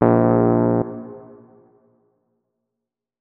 electric_piano
notes-10.ogg